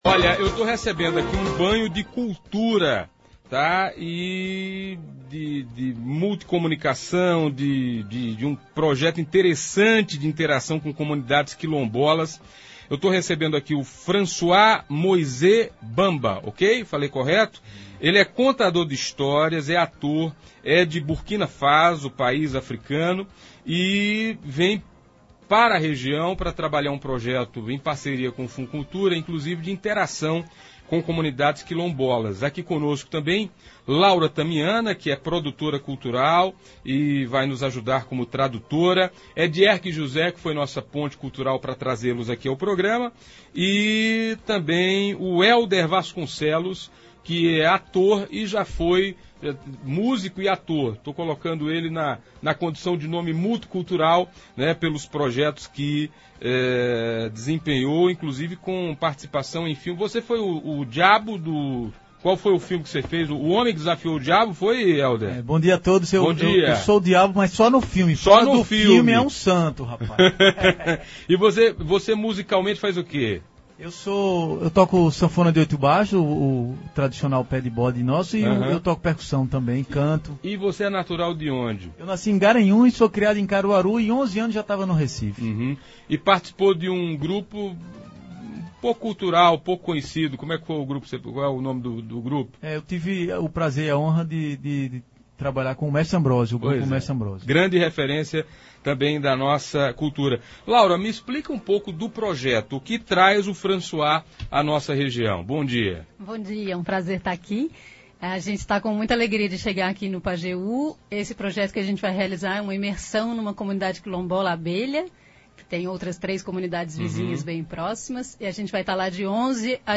Ouça abaixo a íntegra da entrevista e ouça um pouco da arte que eles promovem.